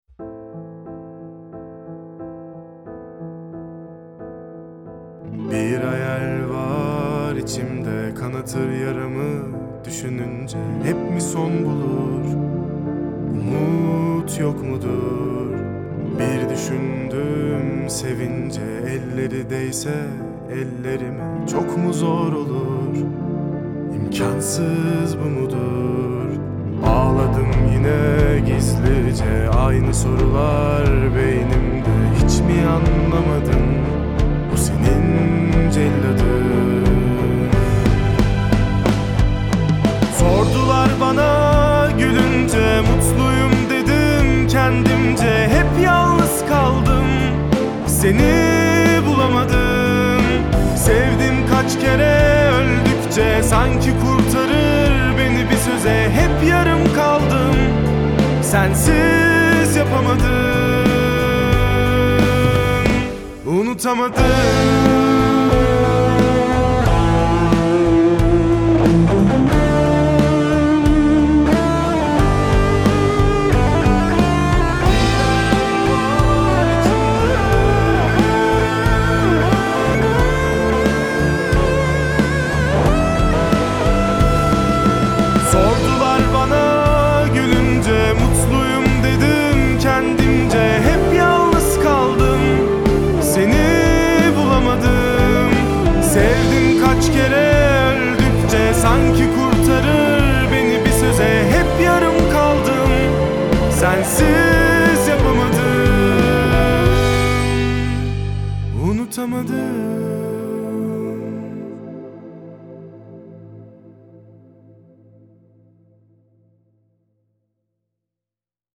duygusal hüzünlü üzgün şarkı.